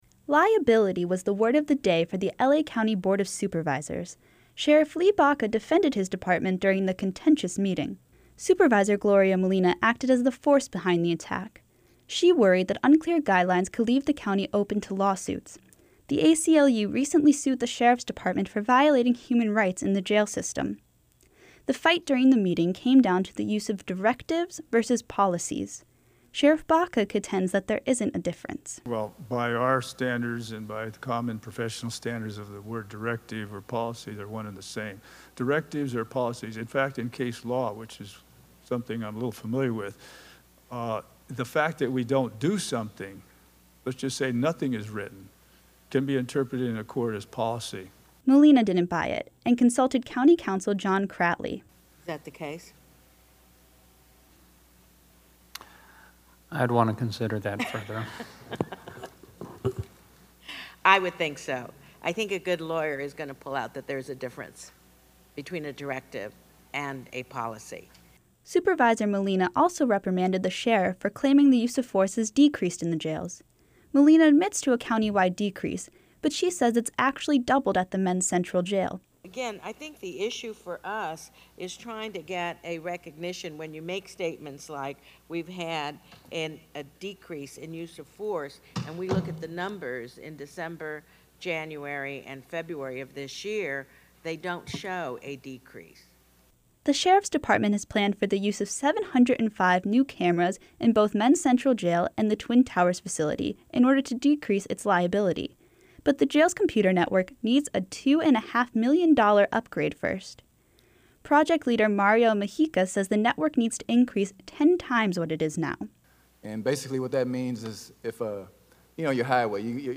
'Liability' was the word of the day for the L.A. County Board of Supervisors. Sheriff Lee Baca defended his department during the contentious meeting.